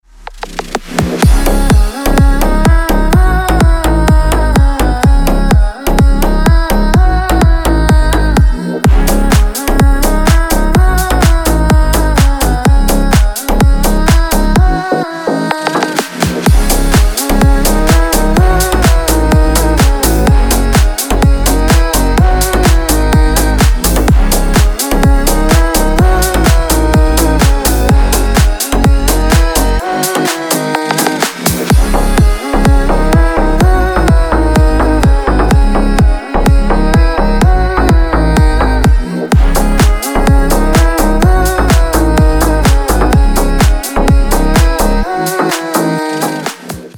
• Качество: Хорошее
• Категория: Рингтоны